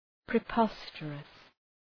Προφορά
{prı’pɒstərəs}